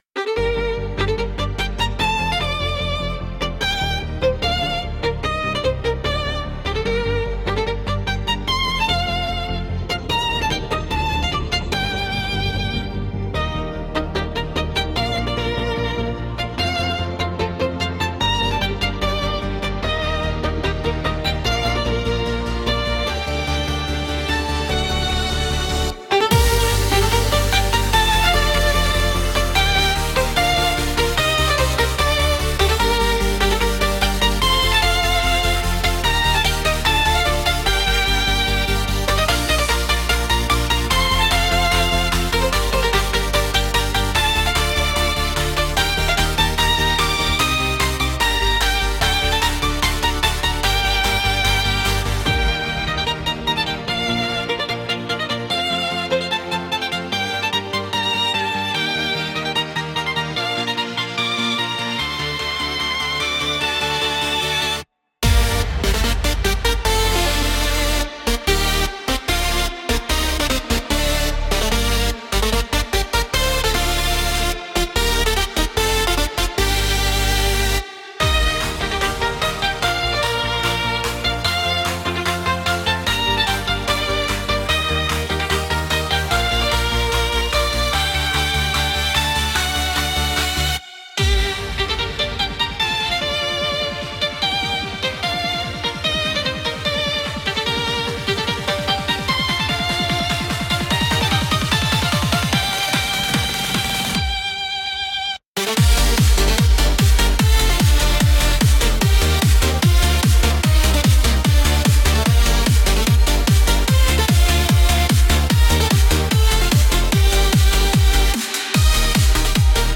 Theme Song: